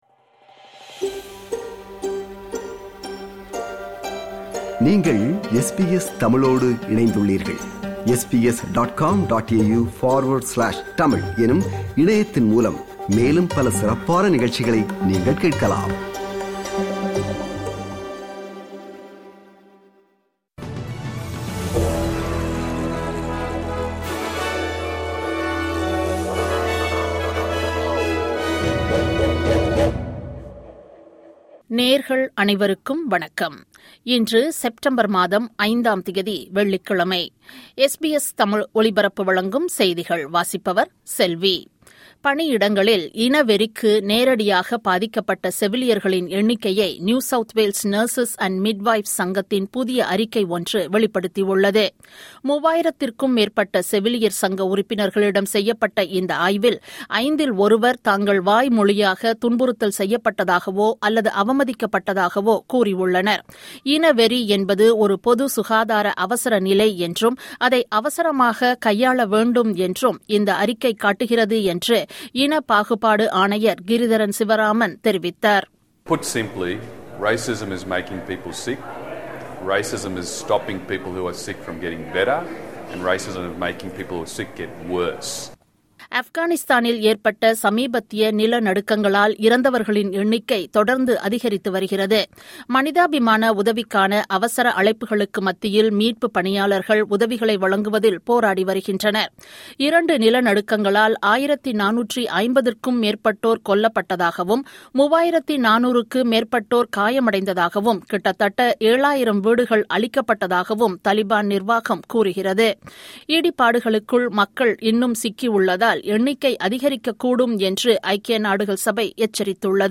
SBS தமிழ் ஒலிபரப்பின் இன்றைய (வெள்ளிக்கிழமை 5/09/2025) செய்திகள்.